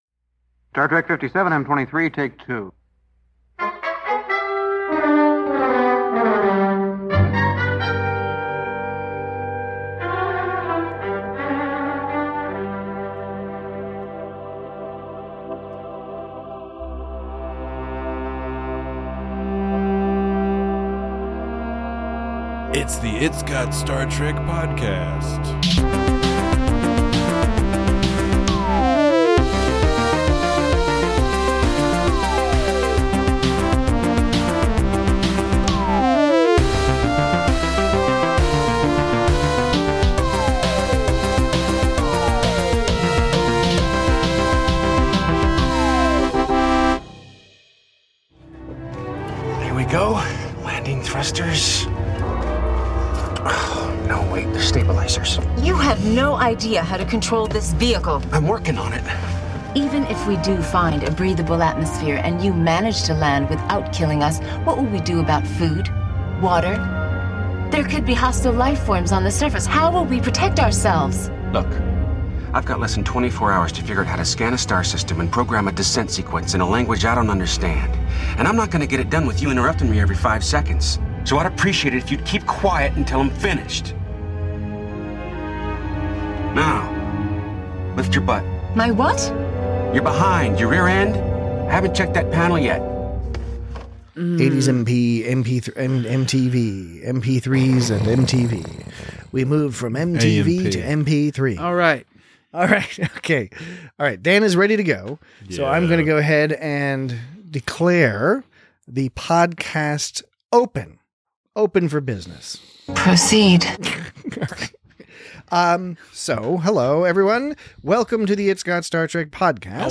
Trip finds himself trapped in a dimension where he has to play out ancient movie tropes. Join your royalty-free hosts as they discuss a lot of things not related to this Padma Lakshmi-starring episode of Star Trek: Enterprise, as well as a number of things that are related to it. Note that we had an especially large bounty of voicemails this week so if you are keen to jump right to the episode discussion, skip ahead to the 32:30 mark.